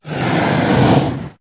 c_hydra_atk2.wav